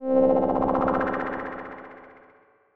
dosuperjump.wav